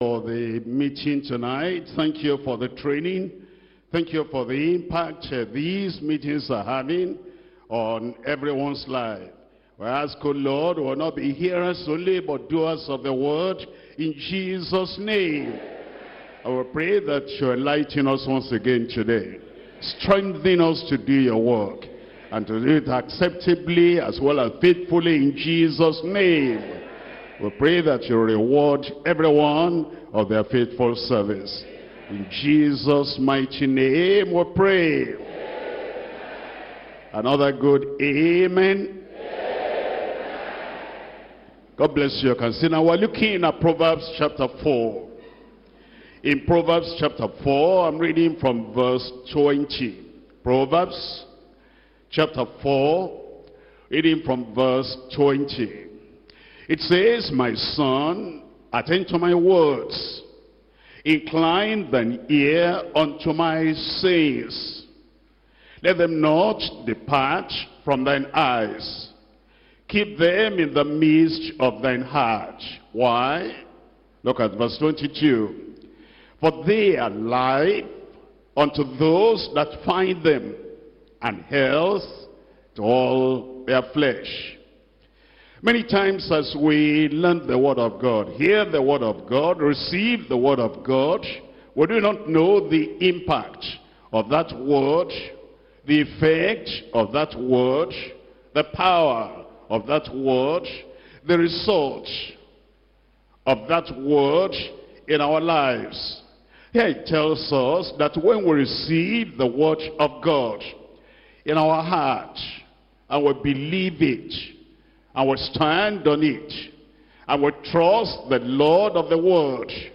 Sermons - Deeper Christian Life Ministry
2018 Workers Training